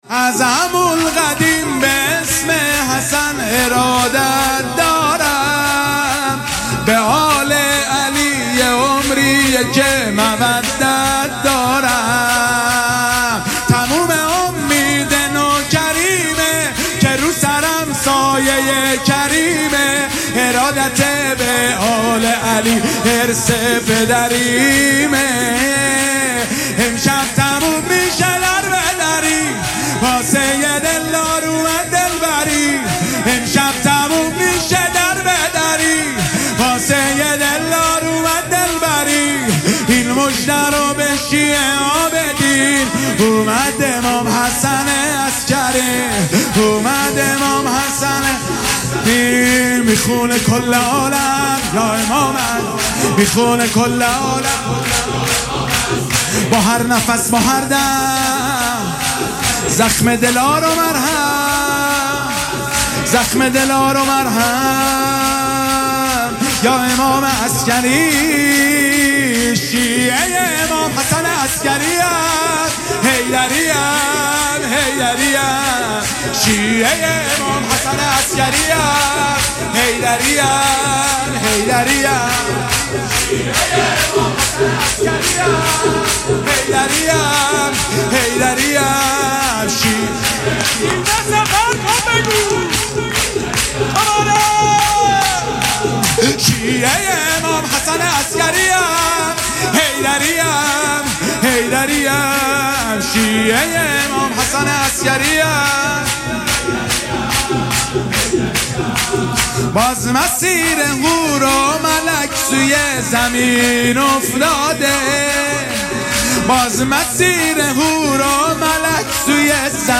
مولودی دلنشین